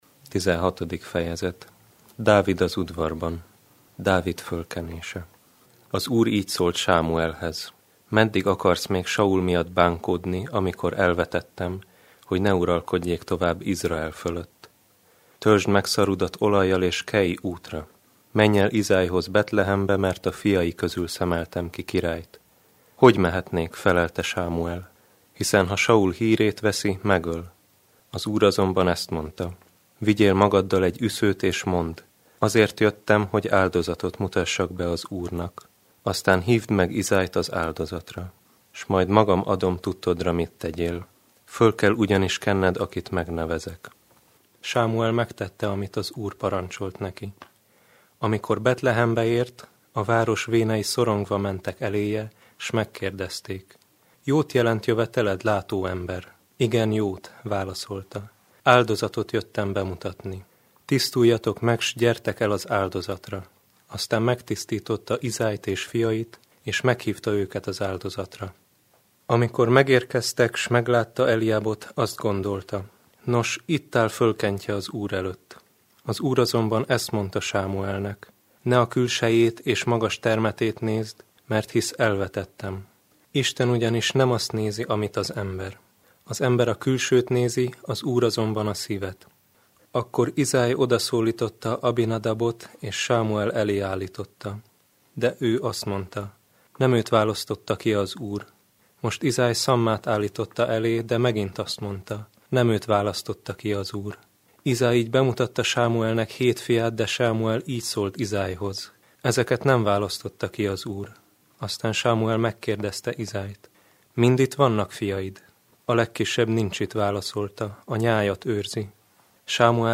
Felolvasók: a  Szombathelyi Egyházmegye hívei